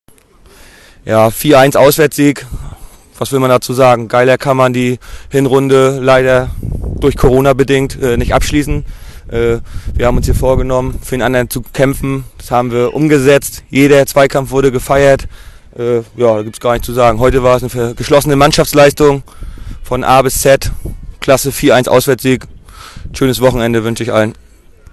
Stimmen